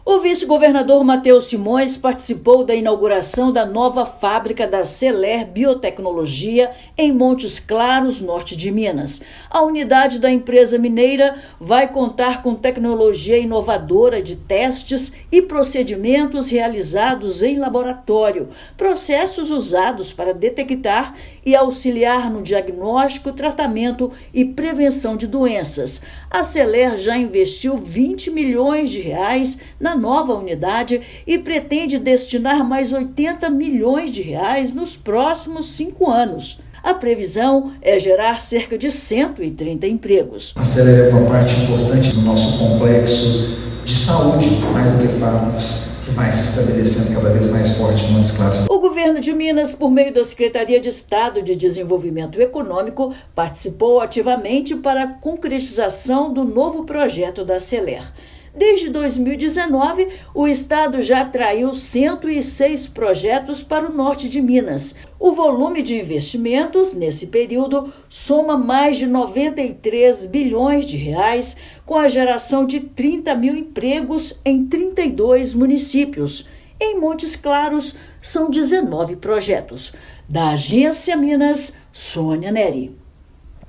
Celer Biotecnologia pretende investir R$ 100 milhões nos próximos cinco anos em projeto de diagnóstico in vitro. Ouça matéria de rádio.